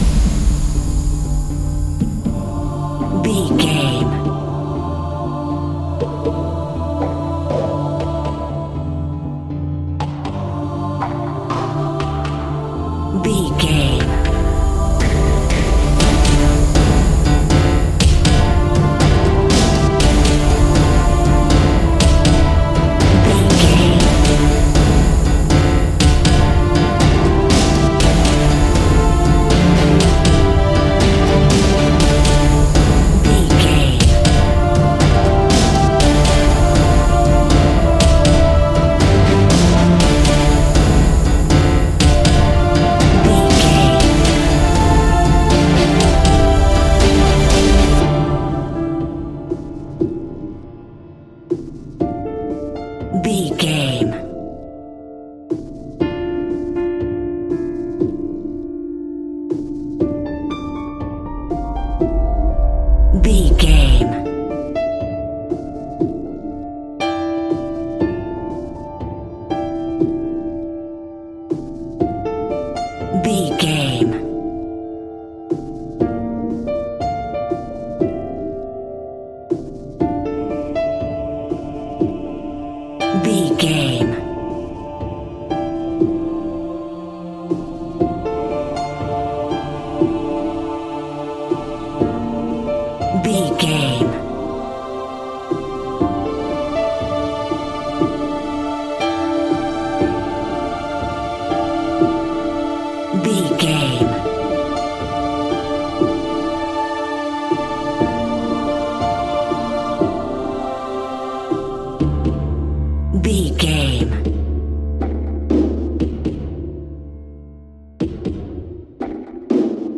Aeolian/Minor
strings
percussion
synthesiser
brass
cello
double bass